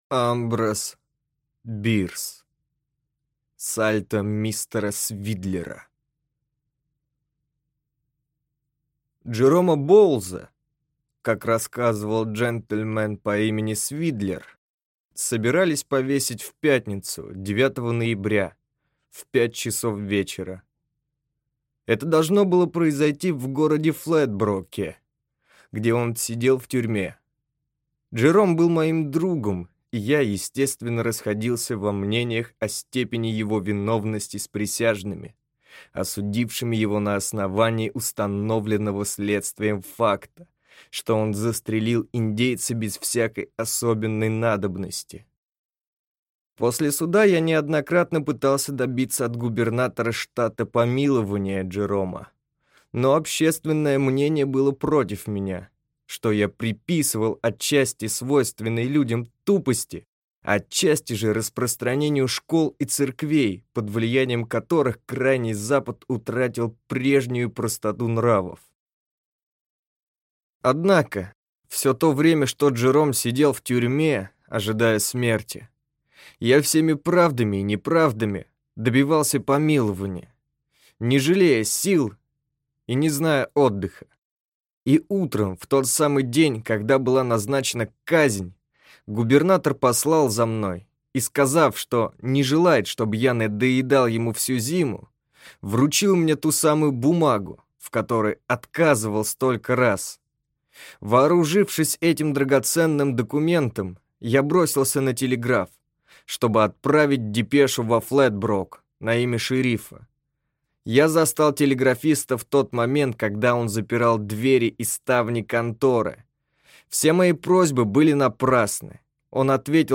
Аудиокнига Сальто мистера Свиддлера | Библиотека аудиокниг